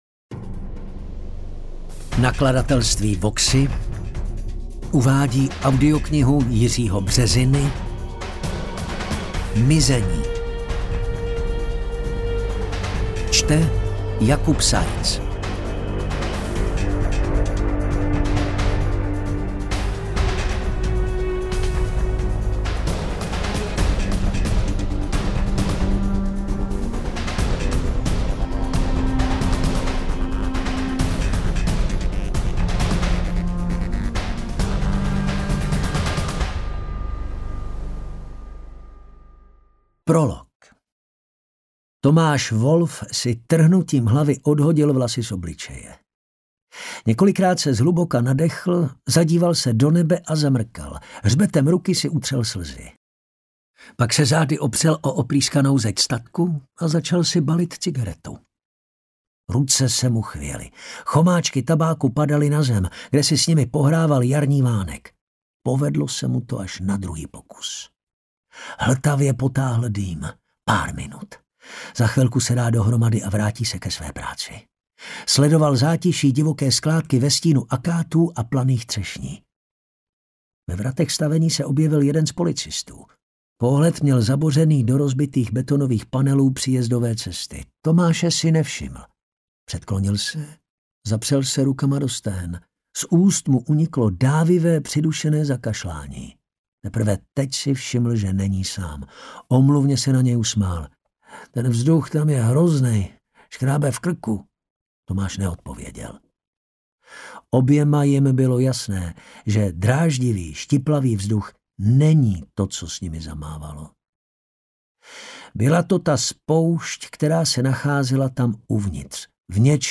detektivky